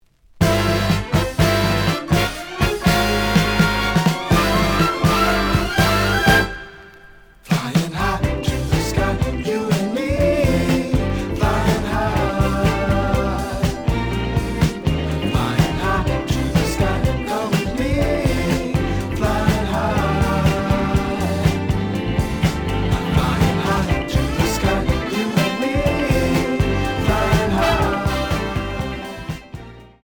The audio sample is recorded from the actual item.
●Genre: Funk, 70's Funk
Slight edge warp. But doesn't affect playing. Plays good.